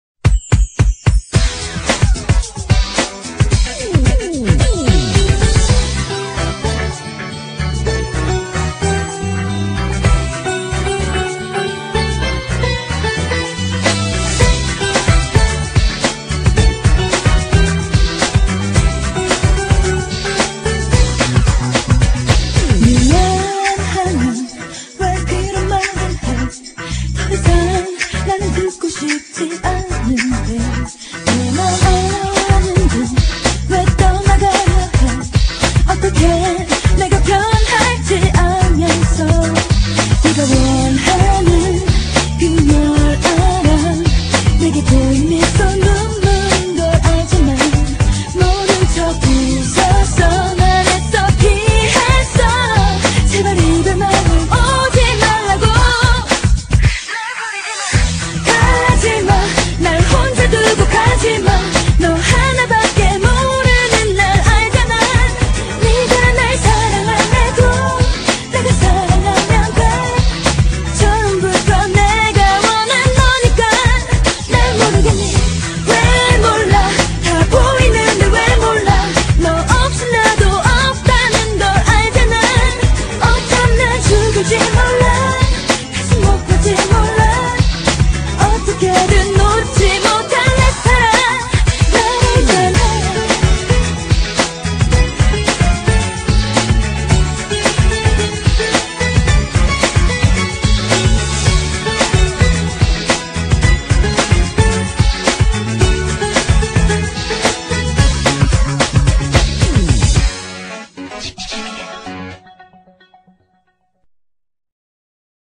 BPM110--1
Audio QualityPerfect (High Quality)